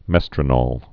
(mĕstrə-nôl, -nōl, -nŏl)